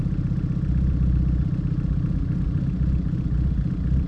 rr3-assets/files/.depot/audio/Vehicles/i4_05/i4_05_idle2.wav
i4_05_idle2.wav